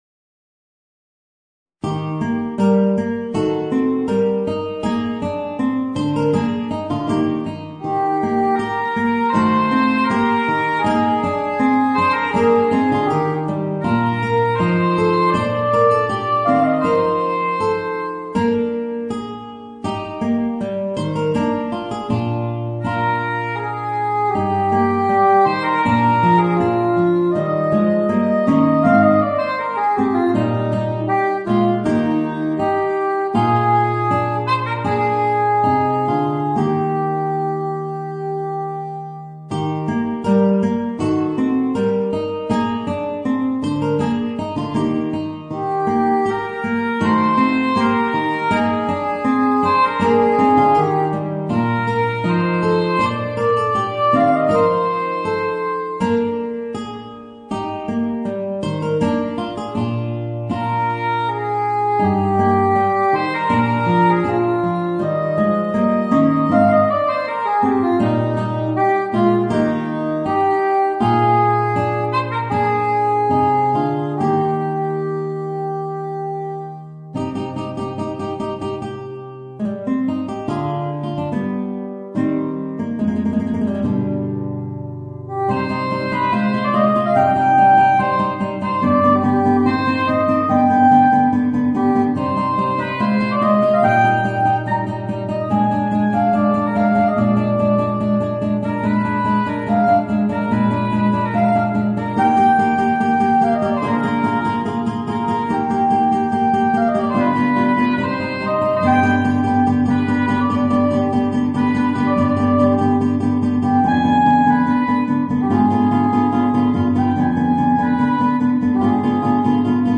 Gitarre & Sopransaxophon